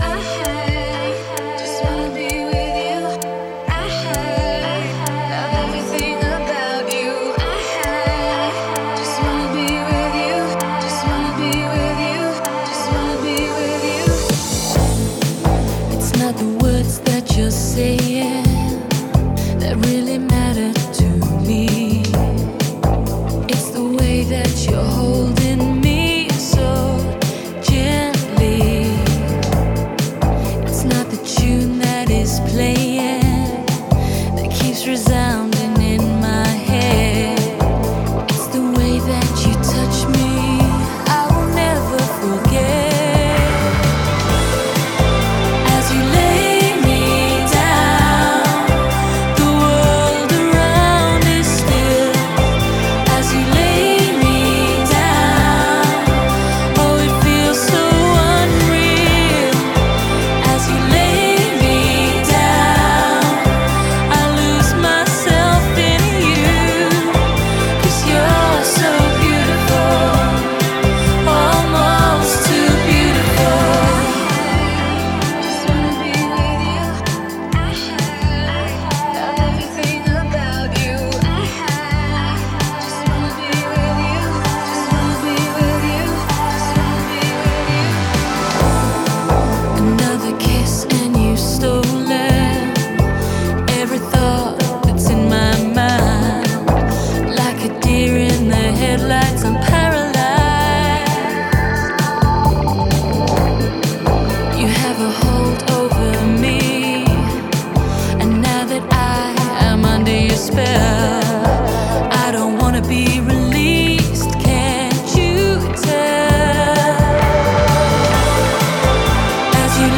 DJ舞曲，都是快节奏、劲爆的音乐。